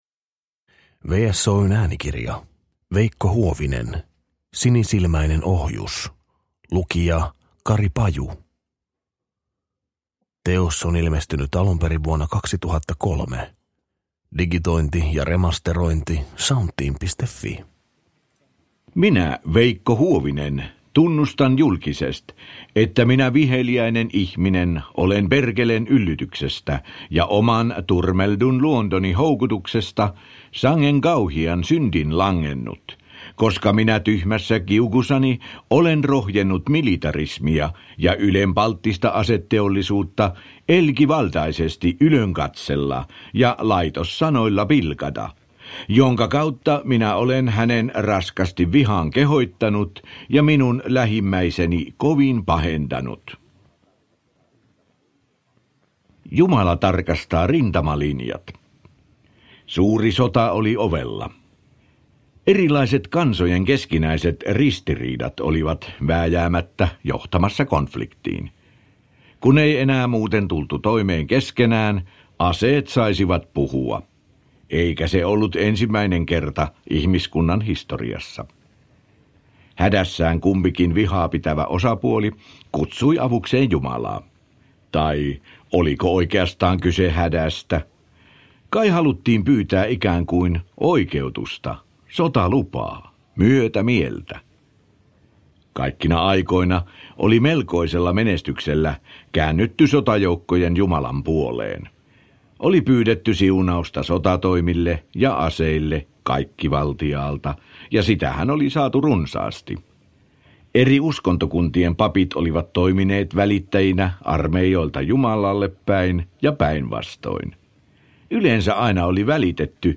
Sinisilmäinen ohjus – Ljudbok